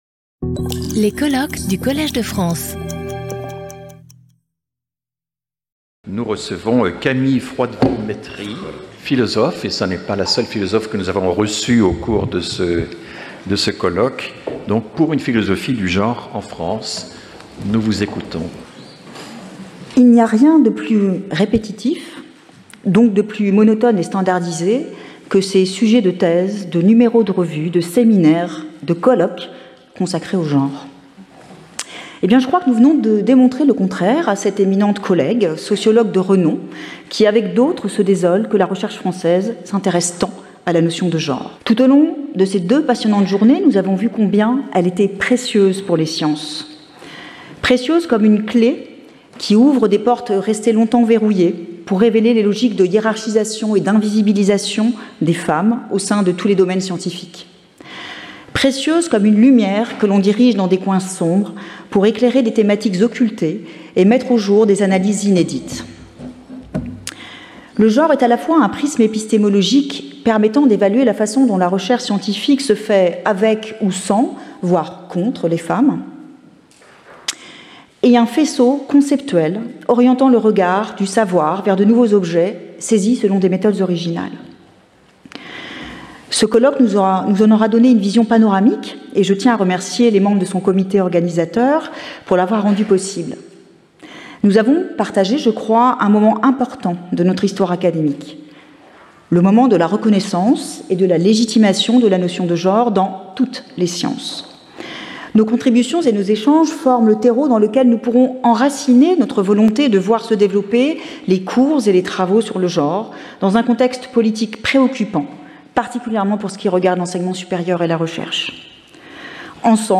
Skip youtube video player Listen to audio Download audio Audio recording Session moderated by François Héran. Each 30 minute presentation is followed by 10 minutes of discussion. Abstract The notion of gender is a powerful heuristic tool for the social sciences, and now for all sciences.